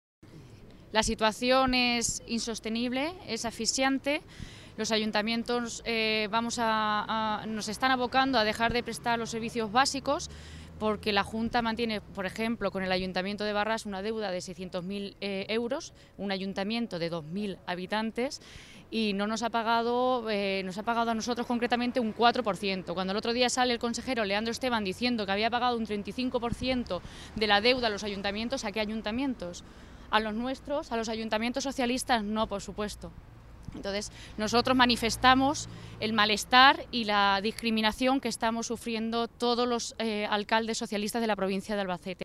Así lo ponían de manifiesto todas las alcaldesas que atendían en Toledo esta mañana a los medios, que señalaban que llevan un año y medio sin cobrar un euro en las partidas que la Junta debe financiar y que son esenciales para sus vecinos, porque afectan a las políticas sociales.
Audio alcaldesa Barrax